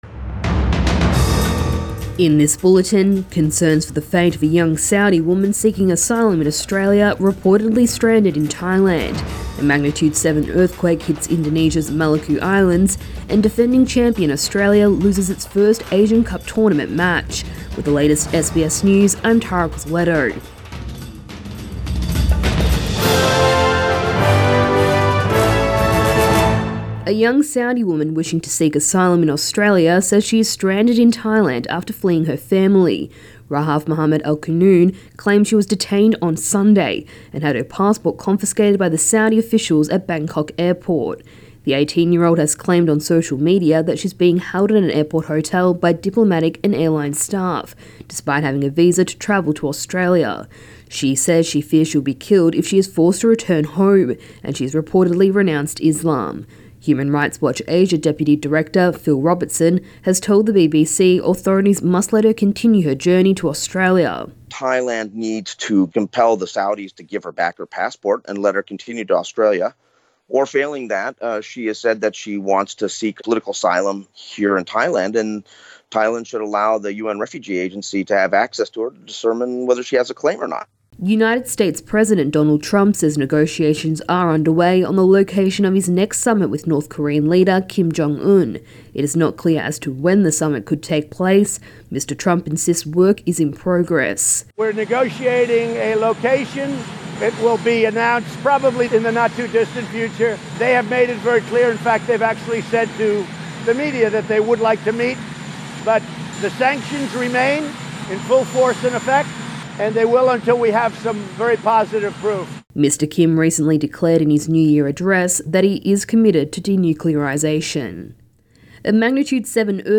AM bulletin 7 January